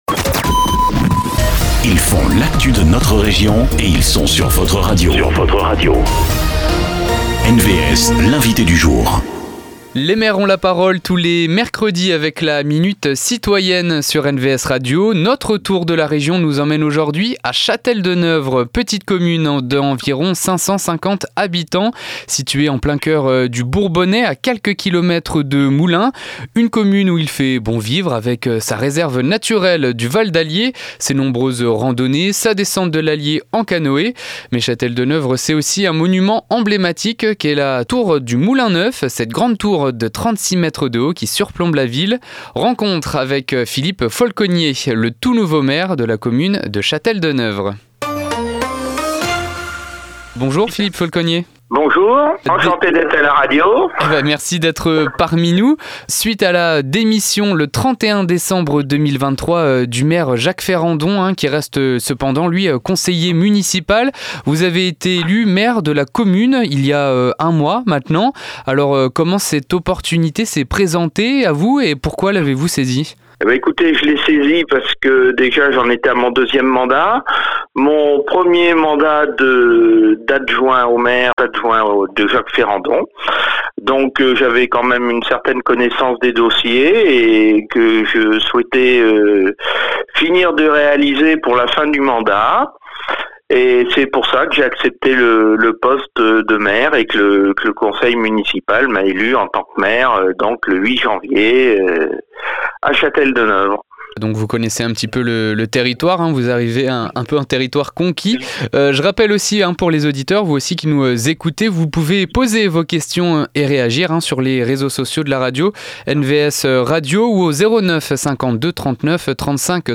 Cette semaine Philippe Faulconnier, maire de Châtel-de-Neuvre dans l’Allier.